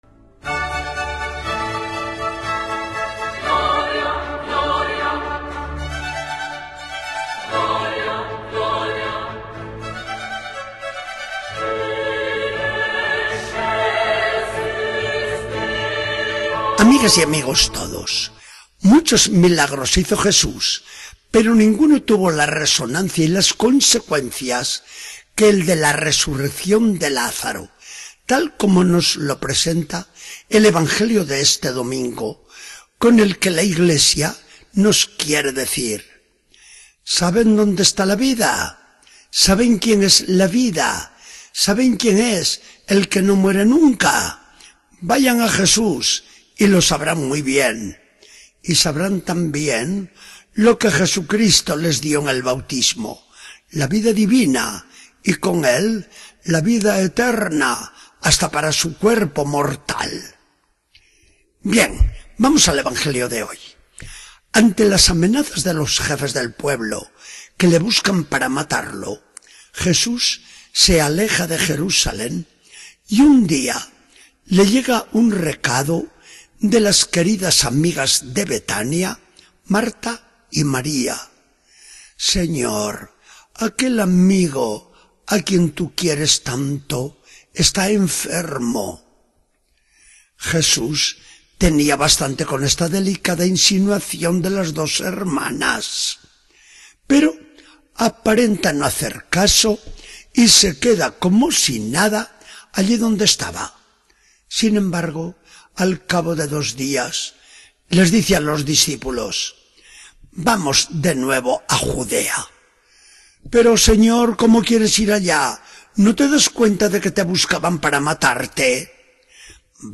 Charla del día 6 de abril de 2014. Del Evangelio según San Juan 11, 1-45.